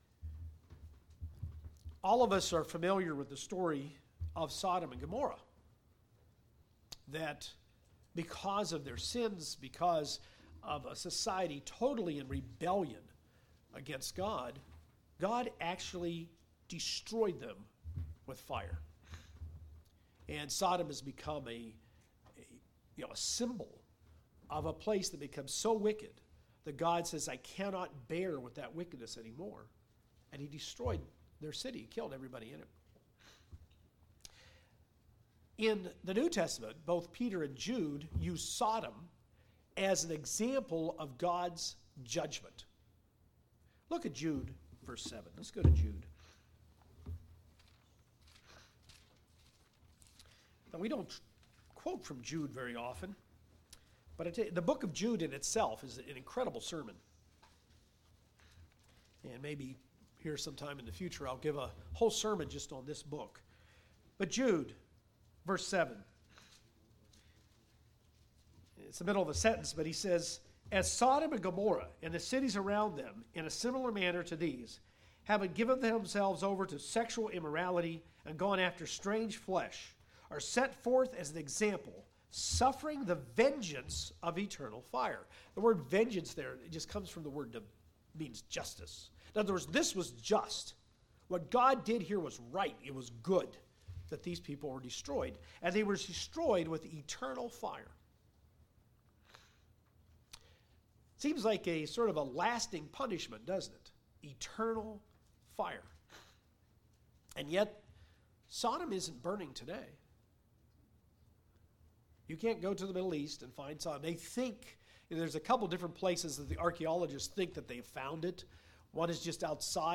This concludes a series on the foundational doctrines found in Hebrews 6:1-2. Eternal judgement is something every human being will be involved in. The concept of eternal judgement as well as temporary judgement are covered in this sermon.